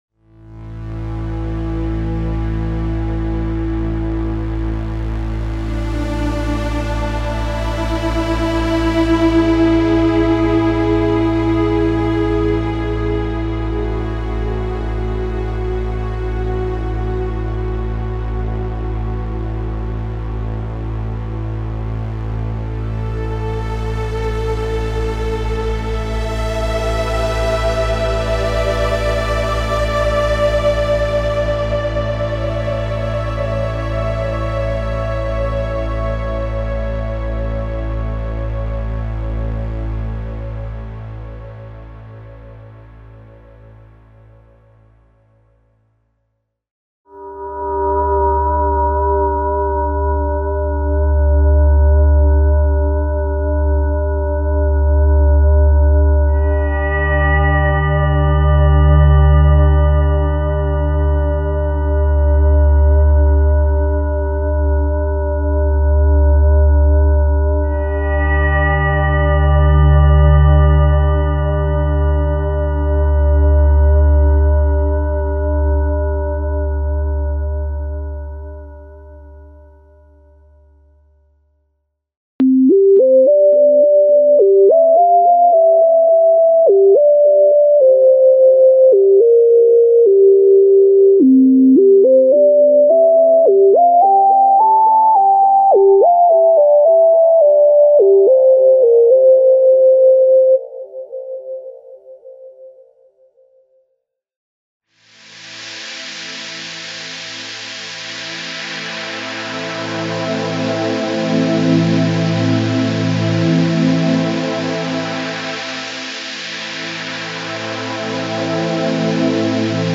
Ambient - special sound programs for ambient and new age music (warm and "ice-cold" synth pads, synth leads, strings, voices, electric & acoustic pianos and piano combinations).
Info: All original K:Works sound programs use internal Kurzweil K2661 ROM samples exclusively, there are no external samples used.